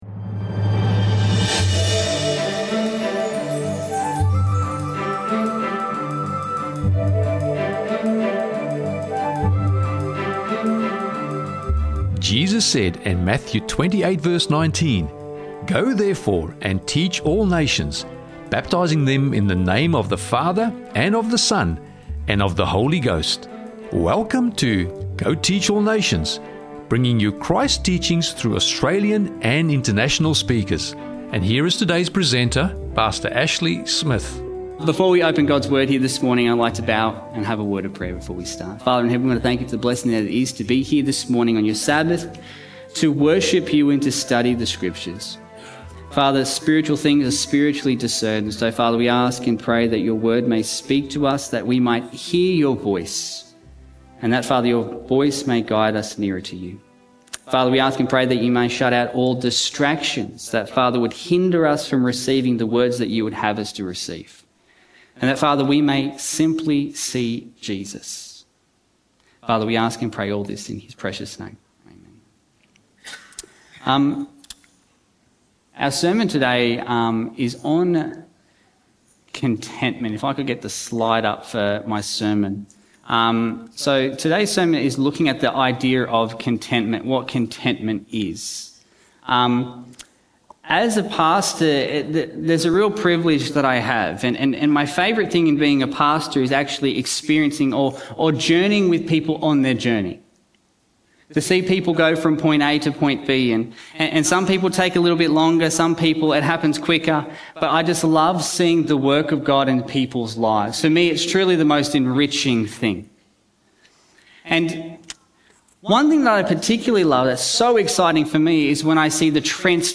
Discovering True Contentment in Christ - Sermon Audio 2602